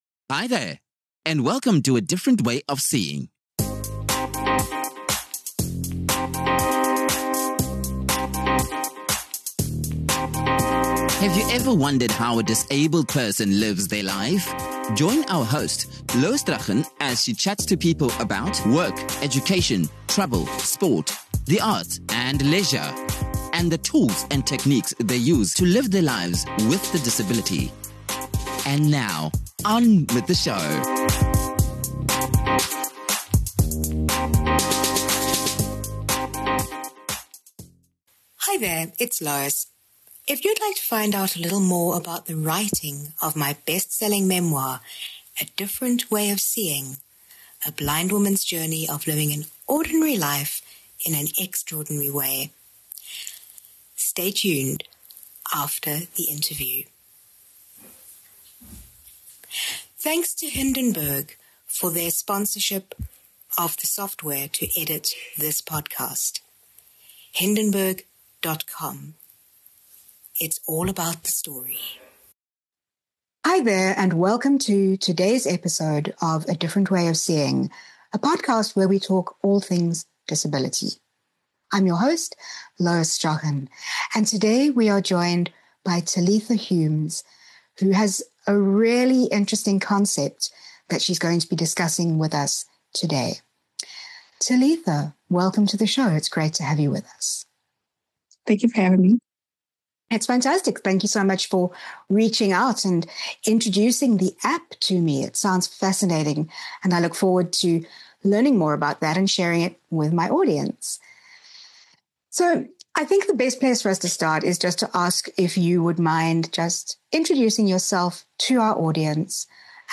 Conversations on opportunities, challenges and lived experiences to foster understanding between disabled and non-disabled communities. Insights into the tools and techniques used by people with disabilities navigating the worlds of work, education, travel, sport, the arts and leisure.